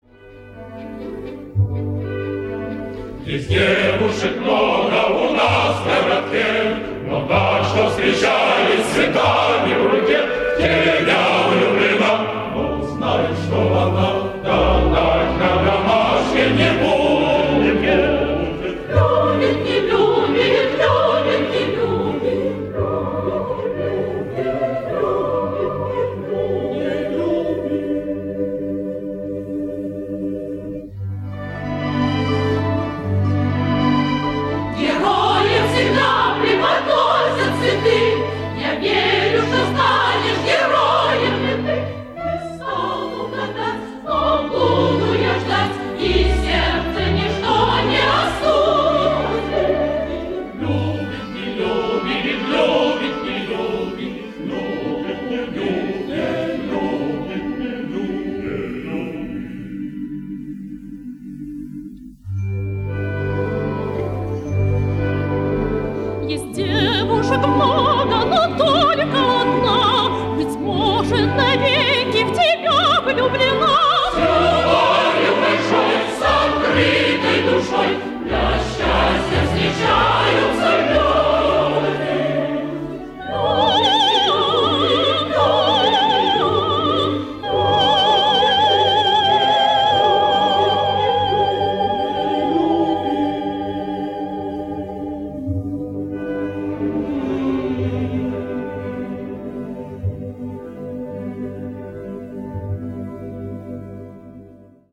Лирико-патриотическая "коляда".